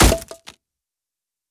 Hit_Wood 05.wav